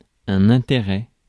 Ääntäminen
IPA: /ɛ̃.te.ʁɛ/